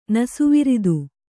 ♪ nasuviridu